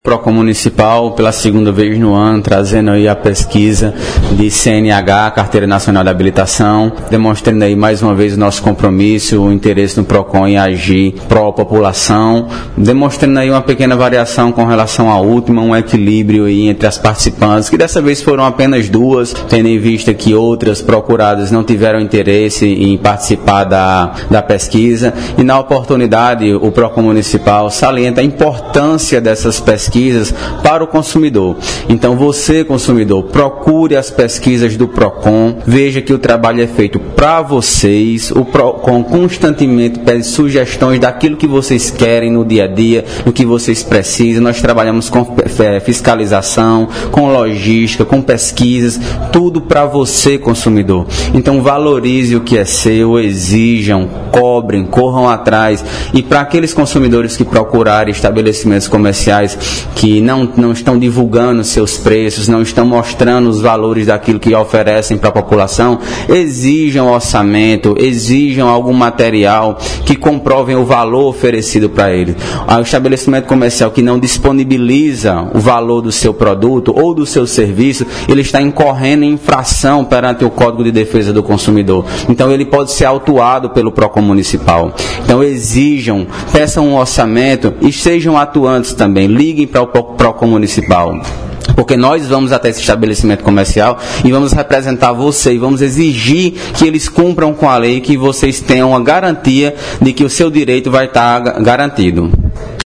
Fala do secretário do PROCON, Bruno Maia –